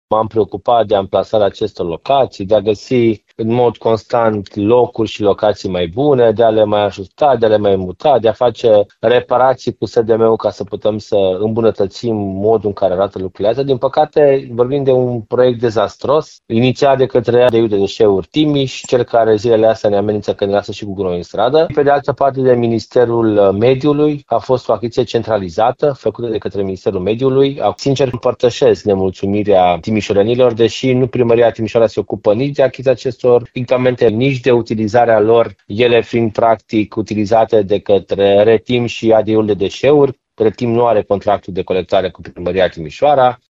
Insulele ecologice, care au înlocuit vechile tomberoane din Timișoara, nu sunt o reușită, spune viceprimarul Ruben Lațcău, după numeroasele sesizări ale cetățenilor legate de proasta funcționare a sistemului.